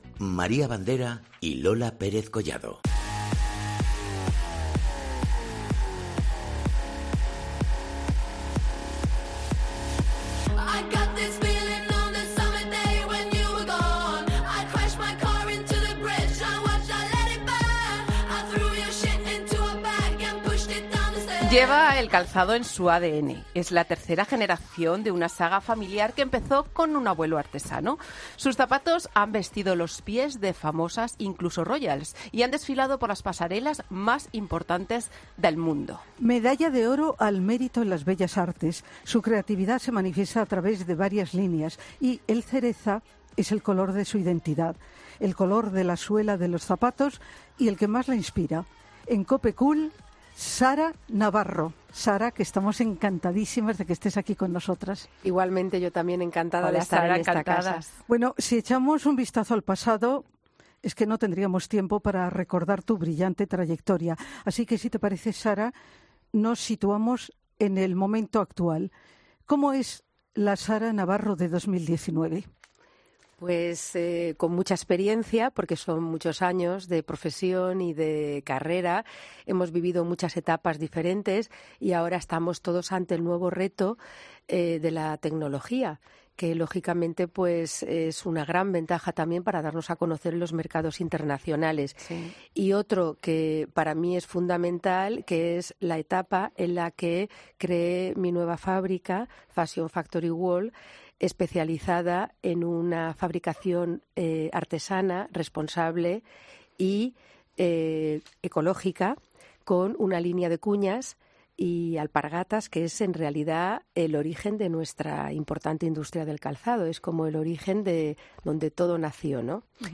AUDIO: Esta entrevista forma parte de la séptima entrega del podcast COPE Cool, con lo último en belleza, moda y decoración